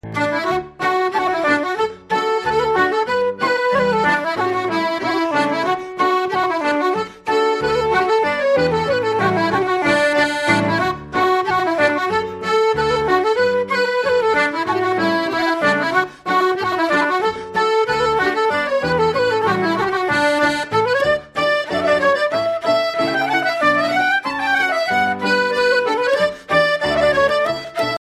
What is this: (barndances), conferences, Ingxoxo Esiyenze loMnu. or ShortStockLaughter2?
(barndances)